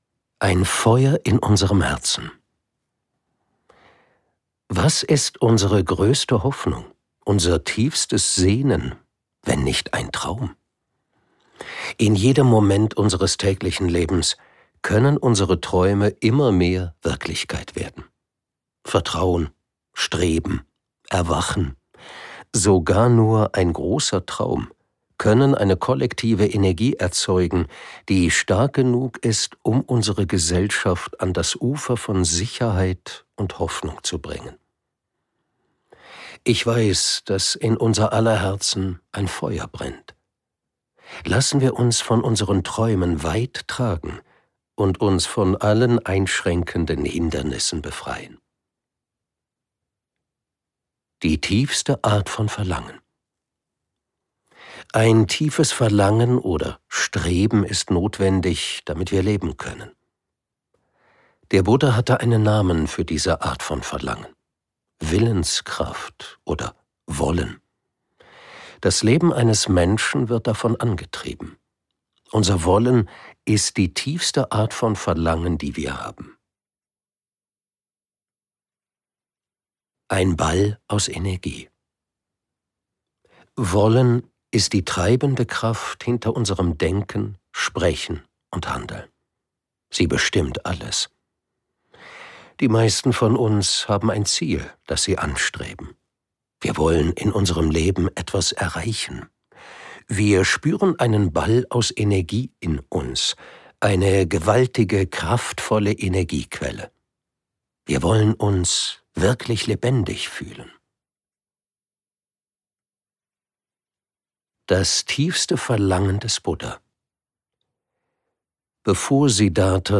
Dieses Hörbuch ist viel mehr als ein Ratgeber, es ist eine Einladung, unser Leben zutiefst lebenswert zu machen – und andere damit zu inspirieren, dasselbe zu tun.
Gekürzt Autorisierte, d.h. von Autor:innen und / oder Verlagen freigegebene, bearbeitete Fassung.